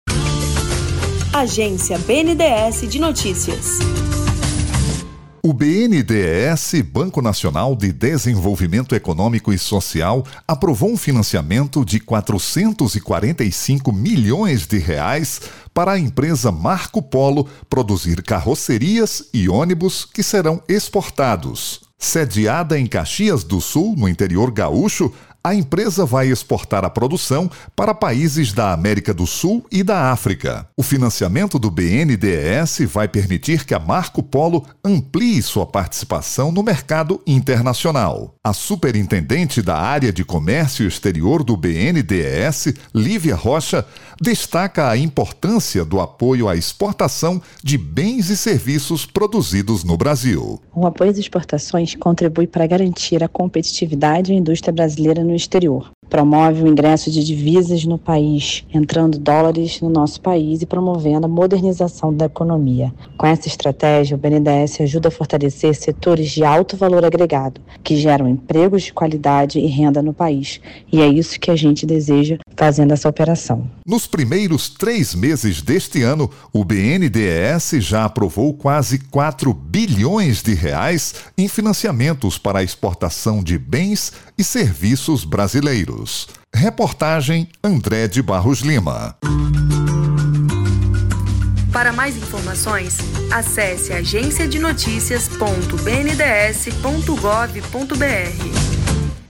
Áudio Release BNDES